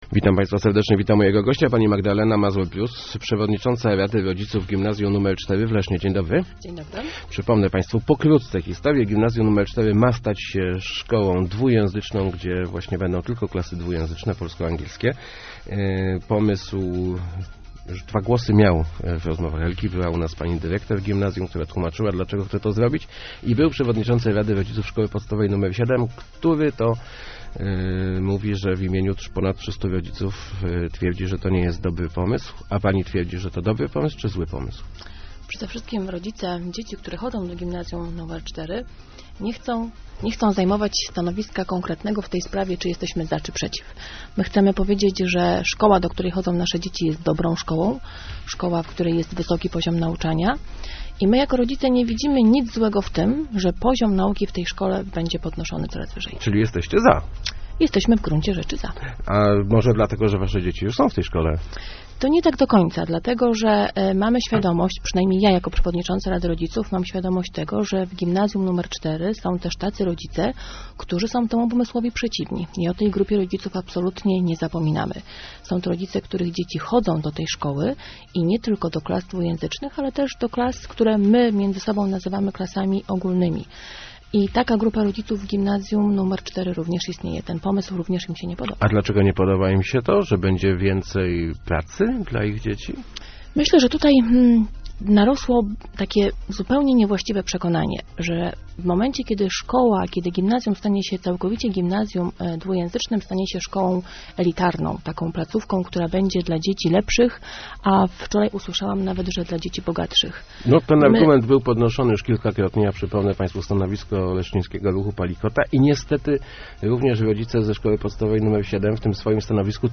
Start arrow Rozmowy Elki arrow Jeszcze jeden głos w sprawie "Czwórki"